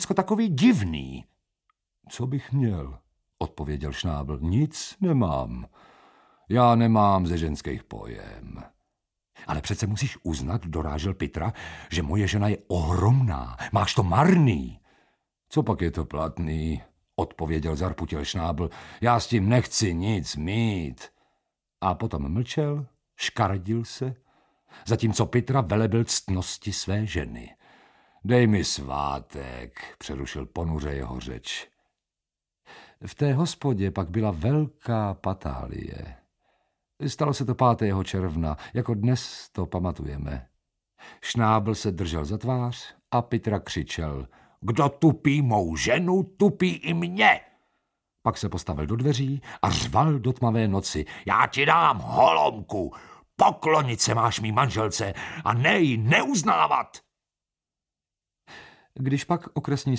Audiobook
Read: Josef Somr